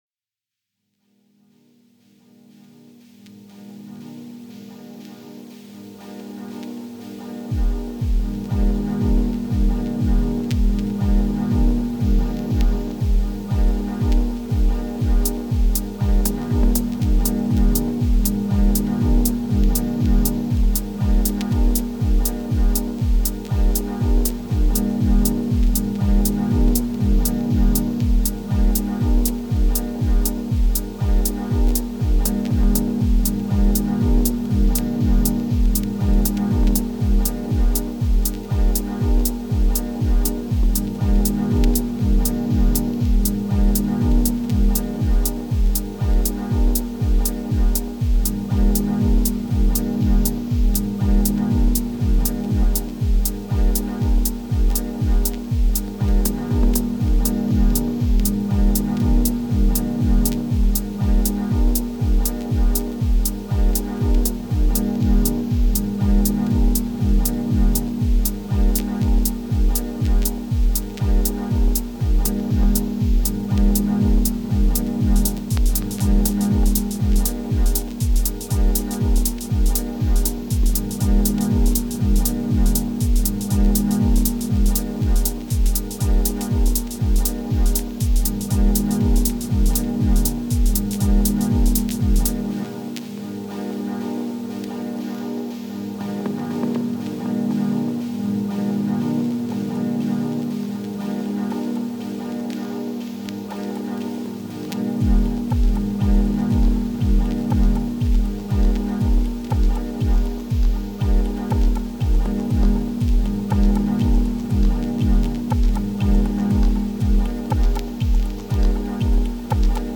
Genre: Dub Techno/Ambient/Deep House.